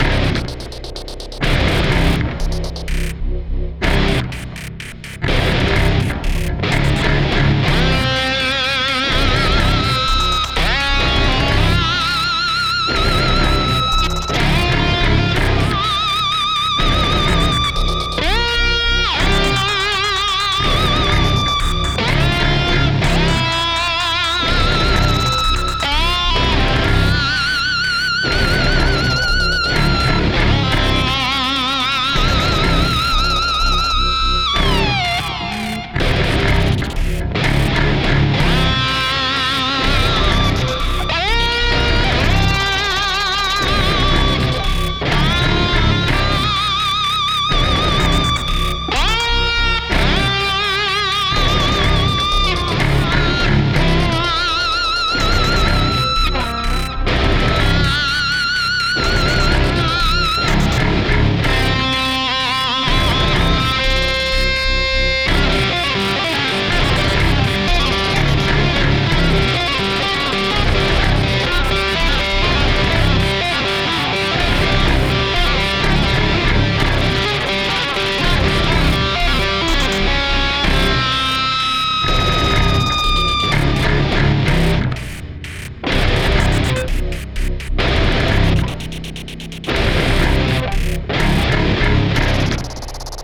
hab mir gestern abend vorgenommen, auch mal wieder zu probieren, etwas gitarre zu spielen.
hab da seit jahren noch eine schöne schekter mit sustaniac (modell jeff loomis) rumfliegen, die ich fast gar nicht benutzt habe...
darksound2.mp3